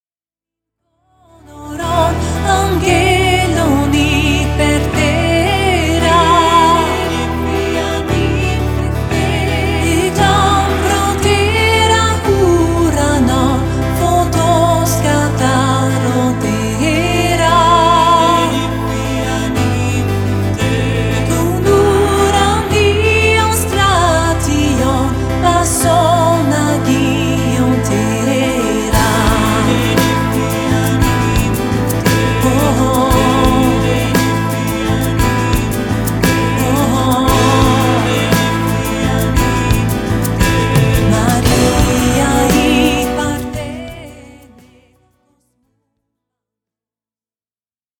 Dans un style pop saupoudré de musique du monde